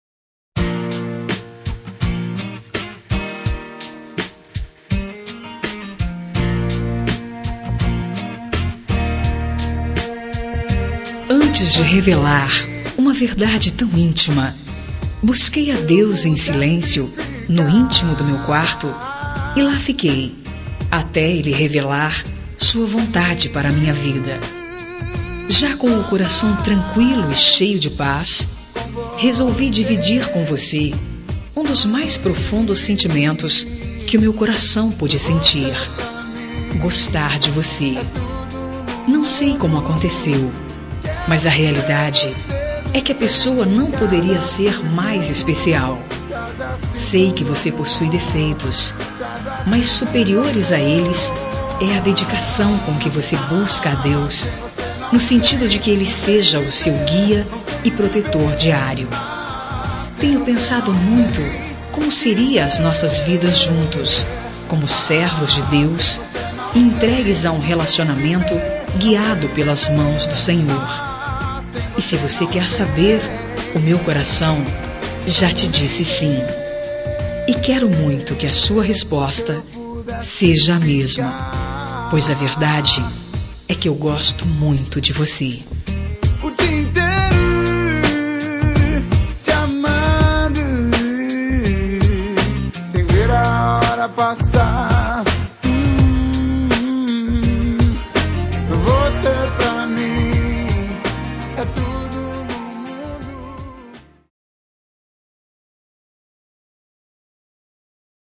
Telemensagem de Conquista – Voz Feminina – Cód: 140105